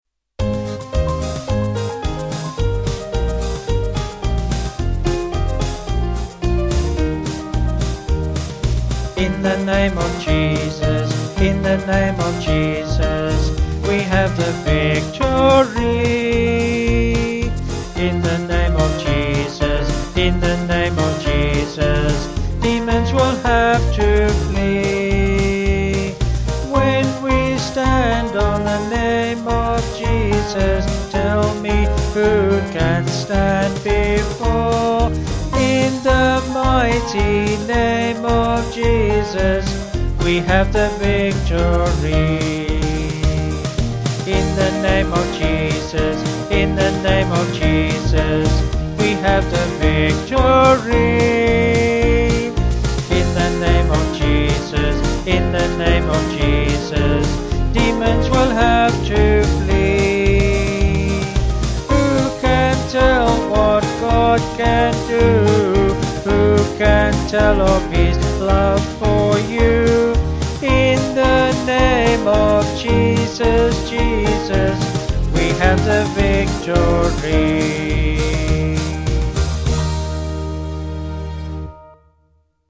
Bluegrass
Vocals and Band   219.9kb